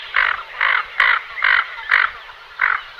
Garrot à oeil d'or
Bucephala clangula